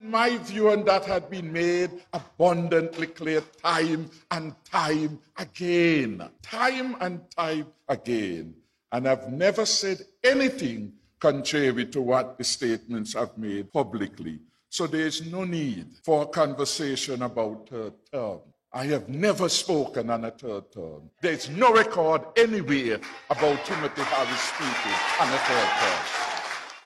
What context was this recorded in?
Prime Minister of St. Kitts-Nevis and Leader of the Peoples Labour Party (PLP), said at a Press Conference on Wednesday that he and his party are totally committed to the ideas of the Team Unity construct.